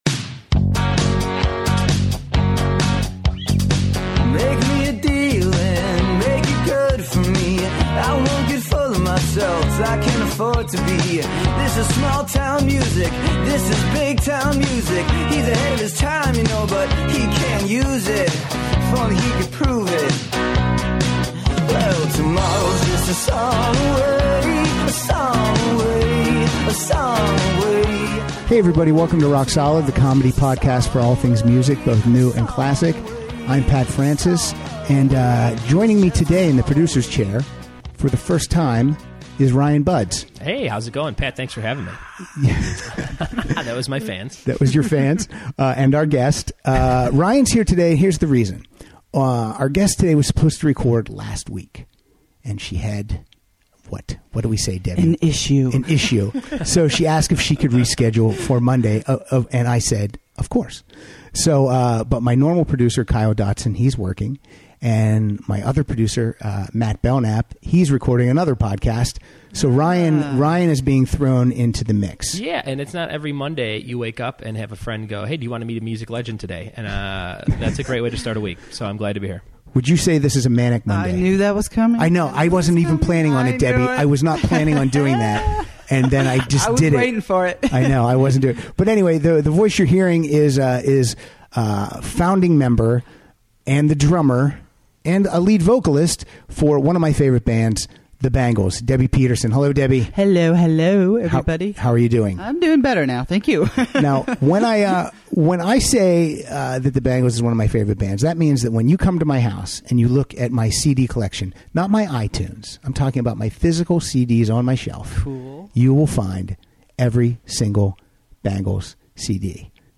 Debbi chats, laughs and sings but sadly refused to do any Egyptian walking.